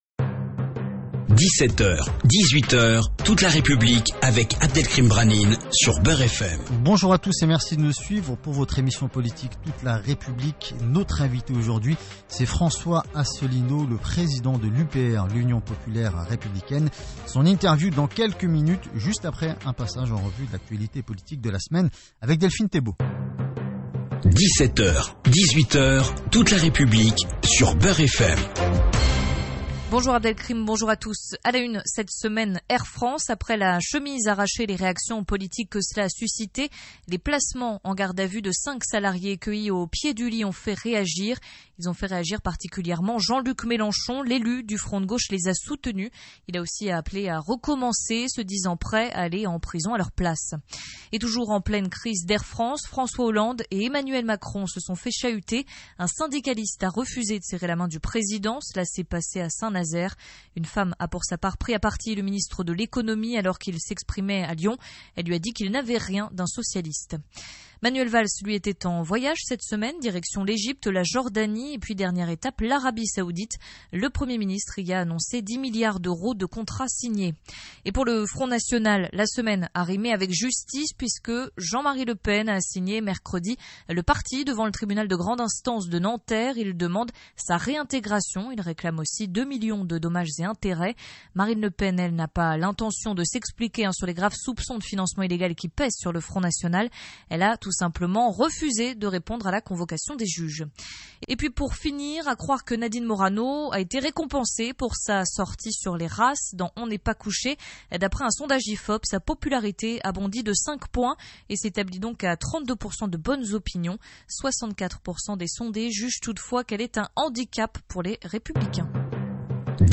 François Asselineau été l’invité de Beur FM dimanche 18 octobre 2015 à 17h00.
Dans la perspective des élections régionales, il a répondu pendant une heure aux questions
francois-asselineau-beurfm-regionales.mp3